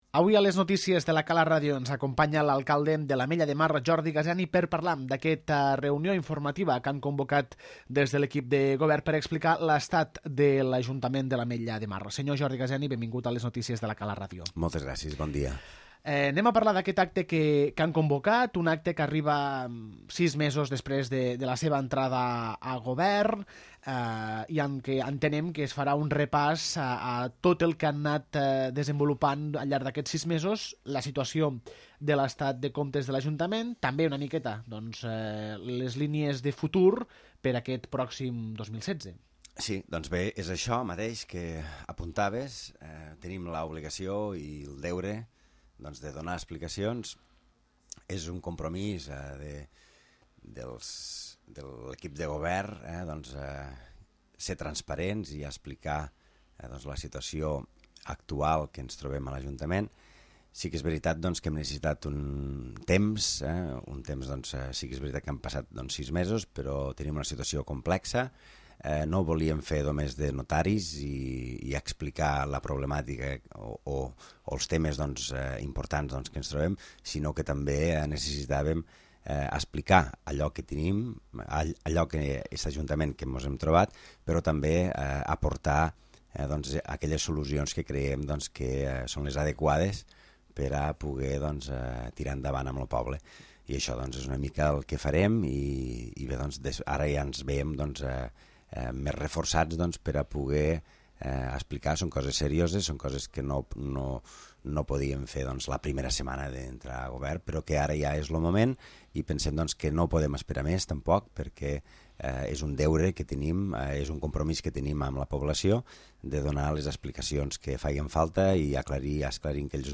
L'entrevista: Jordi Gaseni
Entrevistem a l'alcalde de l'Ametlla de Mar, Jordi Gaseni, per parlar de la reunió informativa per explicar l'estat de l'Ajuntament, que es realitzarà divendres a les 20.00 h a la Sala SCER.